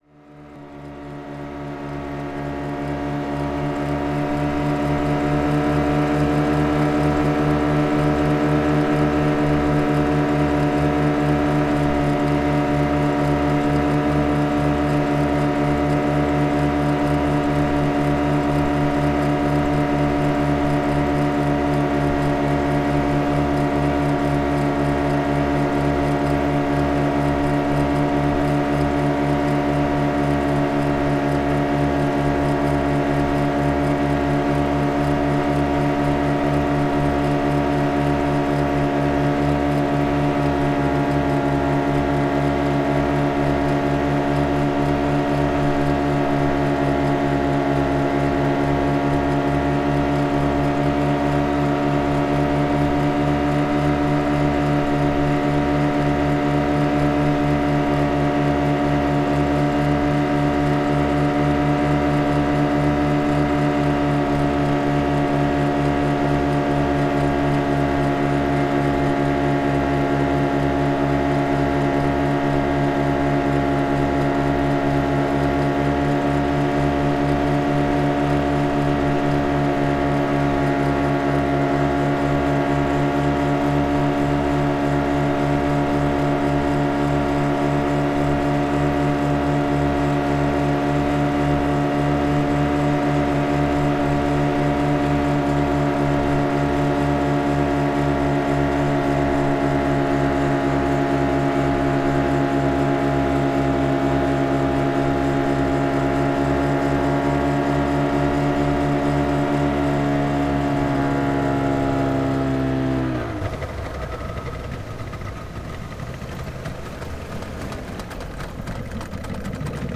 RaceBoat_HighSpeed.wav